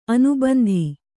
♪ anubandhi